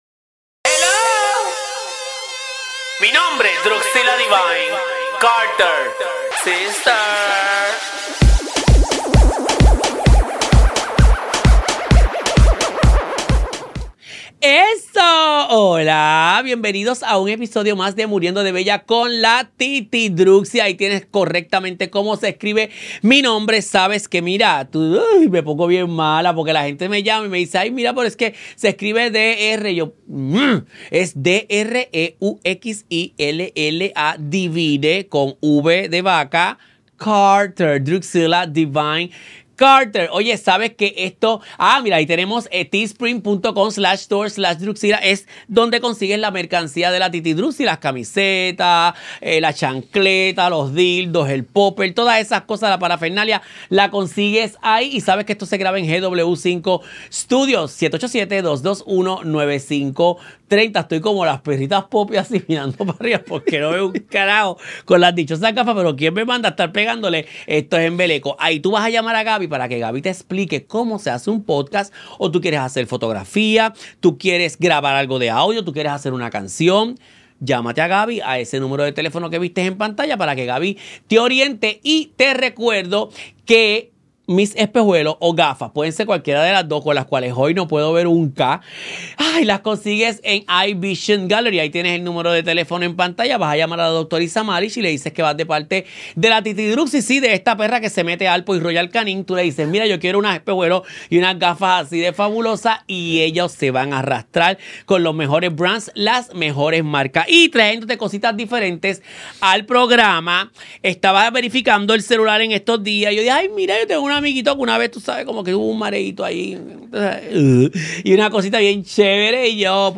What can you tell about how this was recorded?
Grabado en los estudios de GW-Cinco y somos parte del GW5 Network.